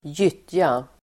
Uttal: [²j'yt:ja]